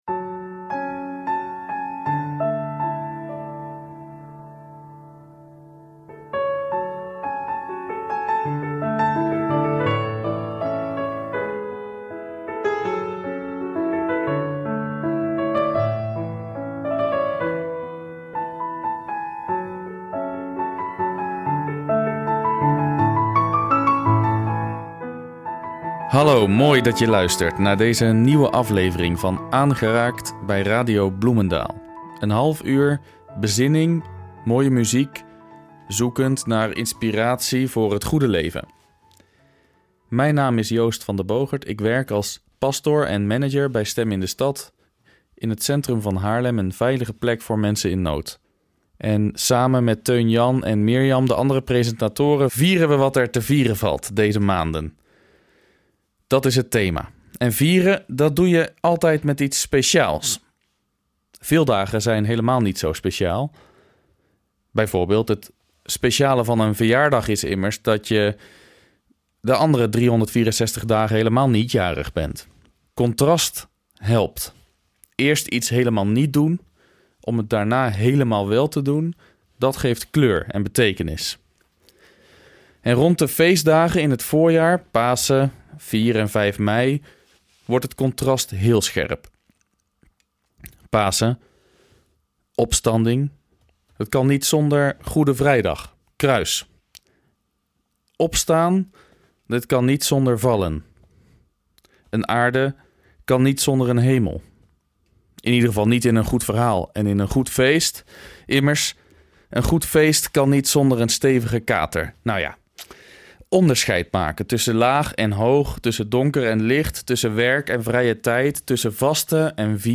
en zoekt zelfs in zijn moerstaal (het Brabantse dialect) naar een antwoord.